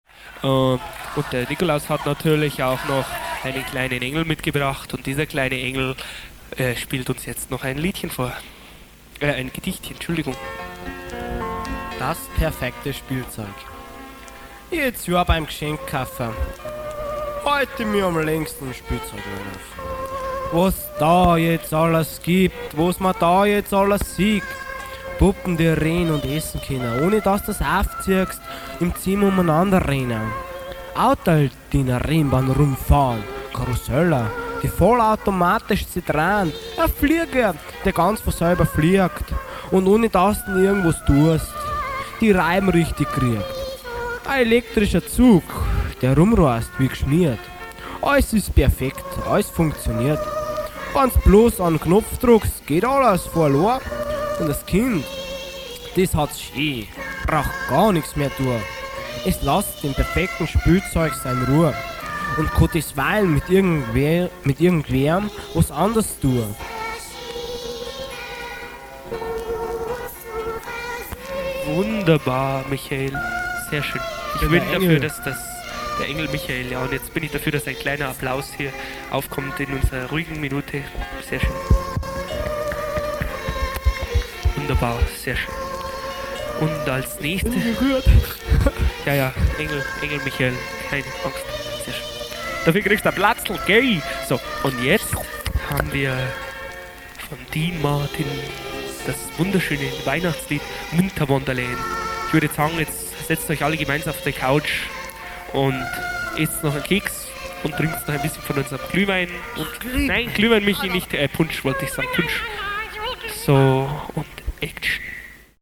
18-EdH-Weihnachtscrew-Frohes-Fest_mit_der_EdH-Gedicht-Das_perfekte_Spielzeug.mp3